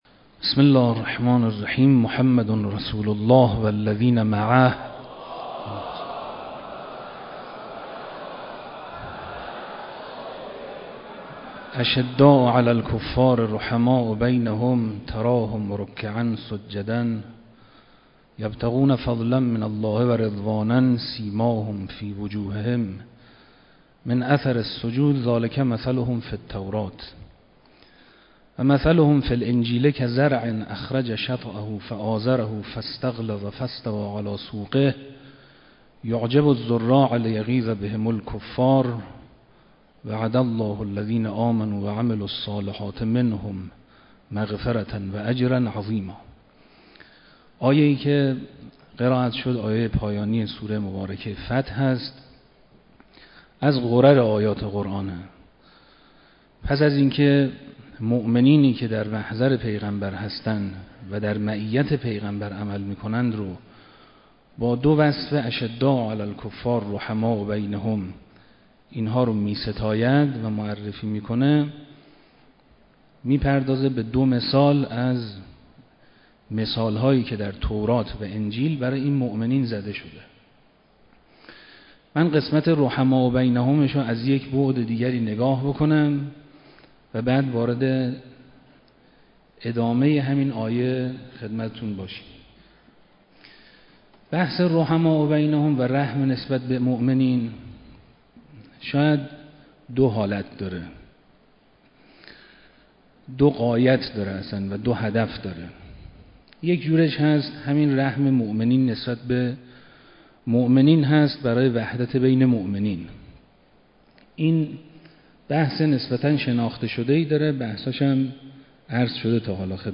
سخنرانی